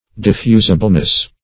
Diffusibleness \Dif*fu"si*ble*ness\, n.